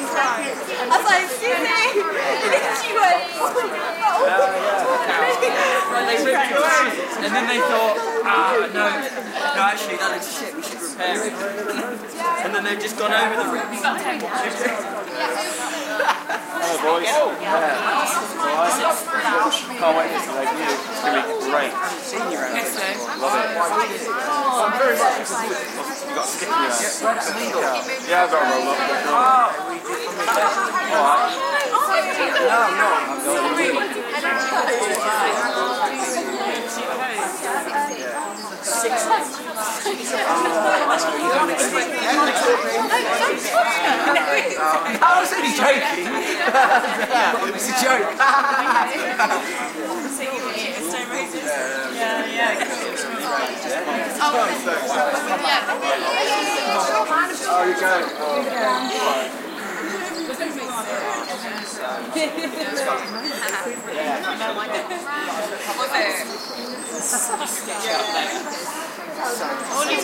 teenageCrowdLoop.ogg